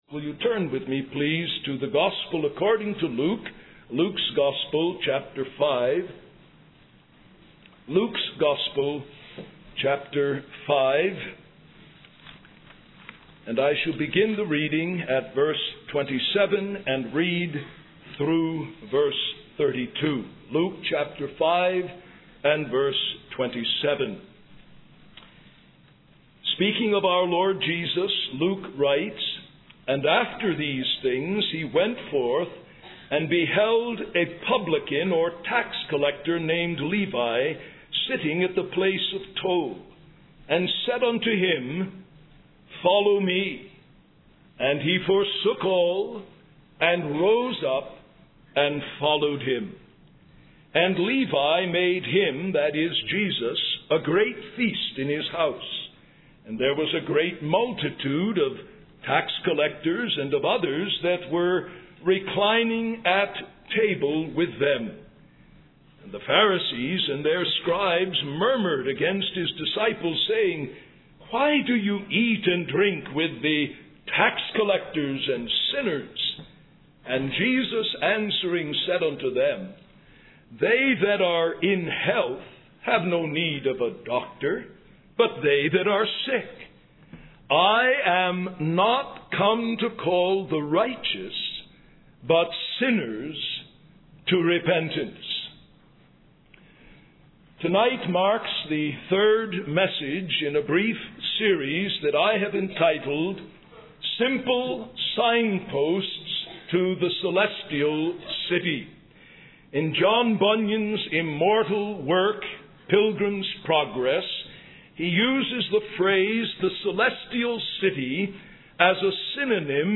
In this sermon, the preacher expresses his desire to serve God and acknowledges the mercy and salvation he has received. He specifically addresses young people, emphasizing the importance of true faith and repentance.